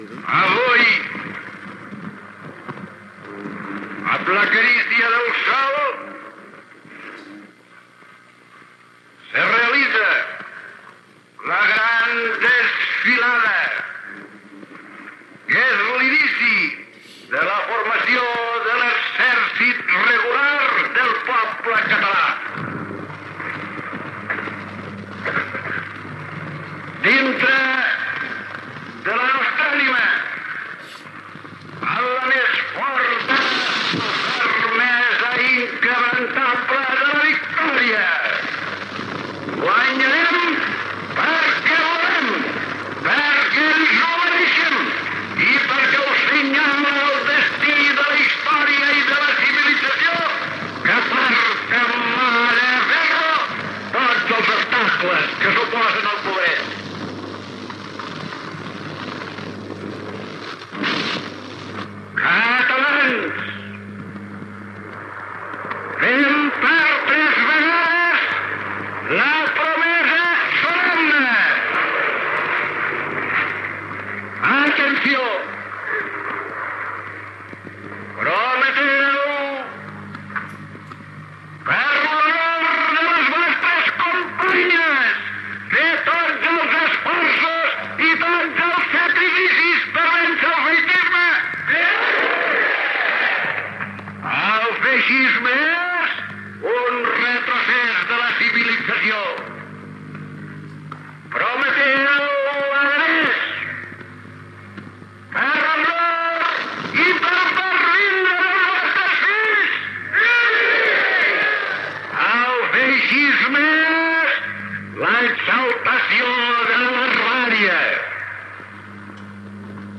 Discurs del president Lluís Companys a la manifestació d’homenatge a l'Exèrcit Popular feta a Barcelona
Enregistrament original en disc de pasta que es troba digitalitzat a la Biblioteca de Catalunya.